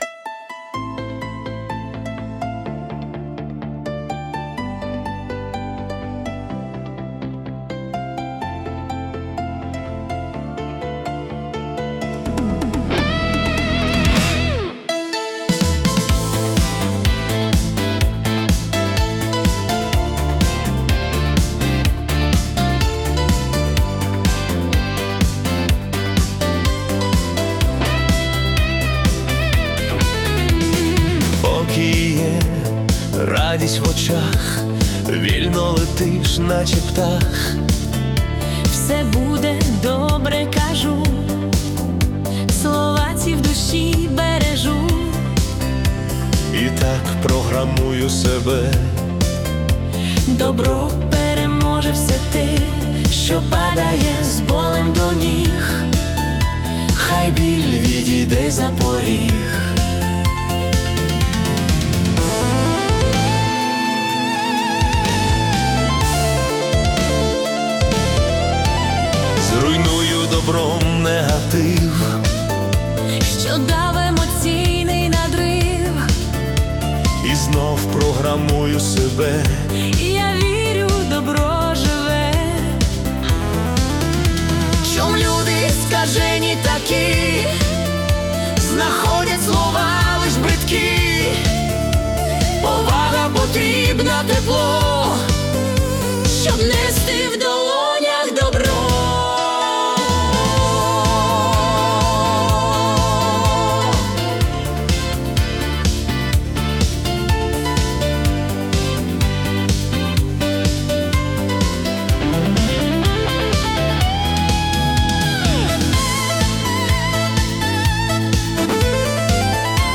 Italo Disco / Motivational
це музичний антидепресант у стилі Italo Disco (125 BPM).